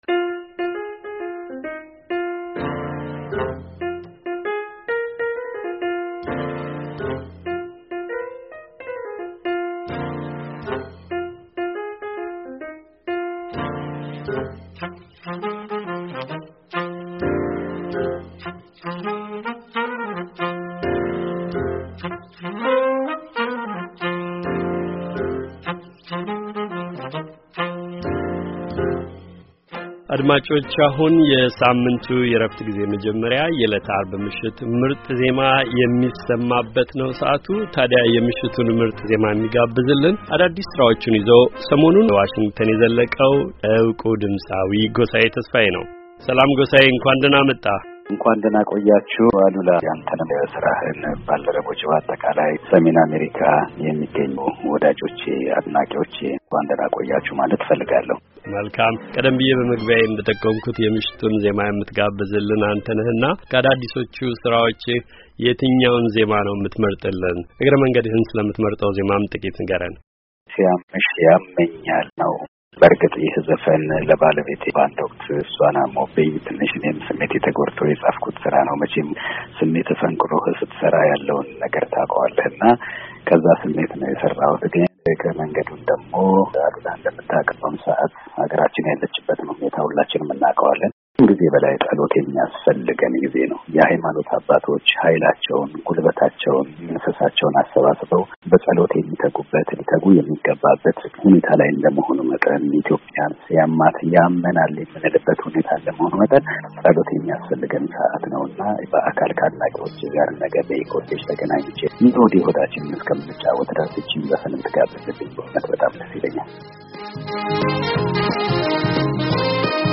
ድምጻዊ